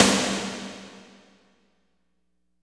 48.09 SNR.wav